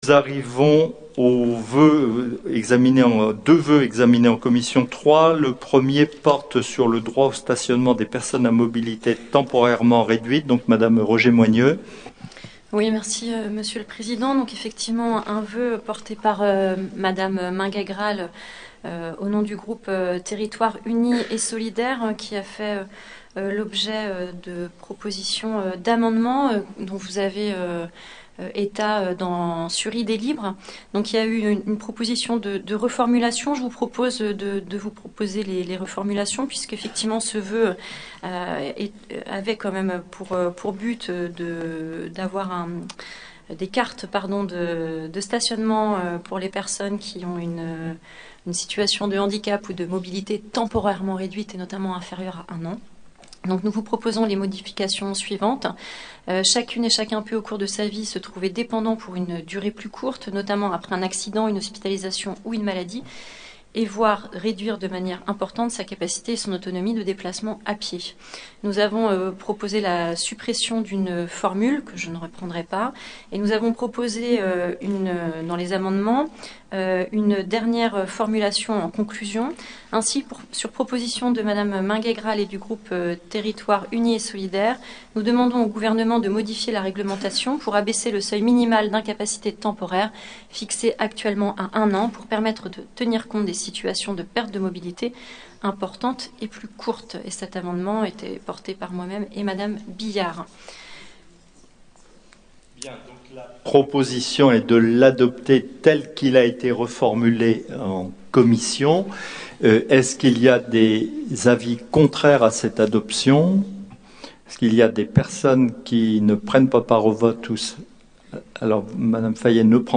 • Assemblée départementale du 17/11/22